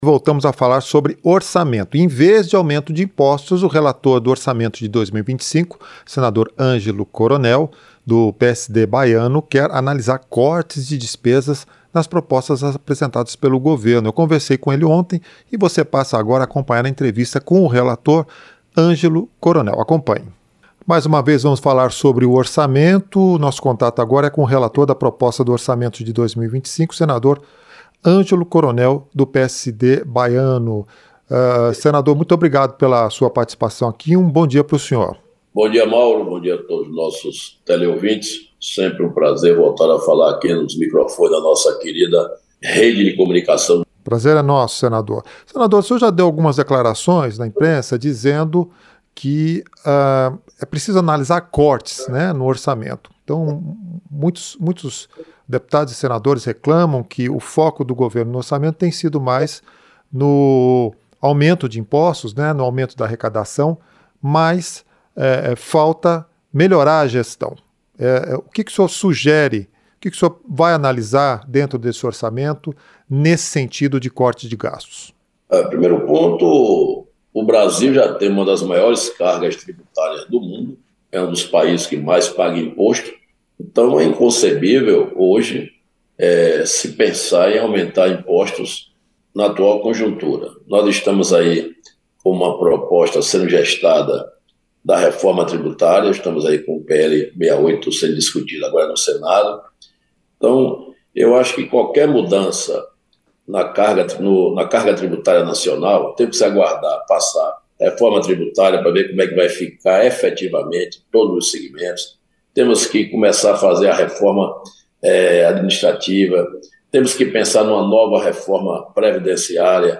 Entrevista - Sen. Angelo Coronel (PSD-BA)